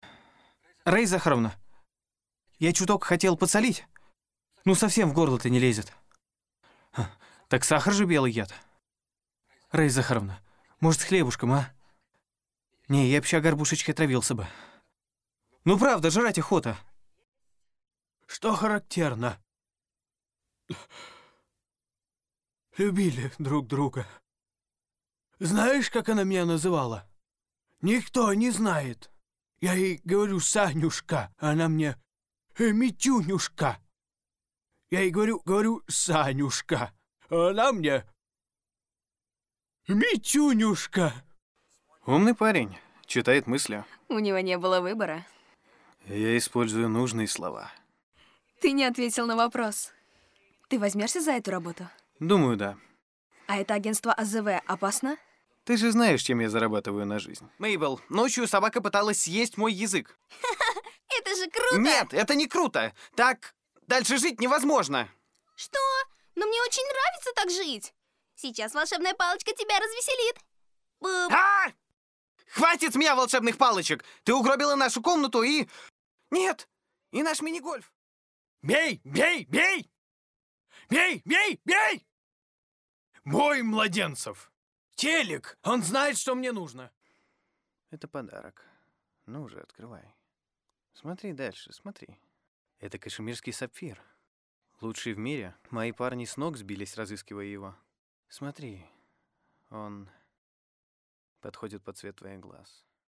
Мужской
Работаю по специальности актер дубляжа и диктор. Озвучиваю кино, рекламу, компьютерные игры, промо-ролики.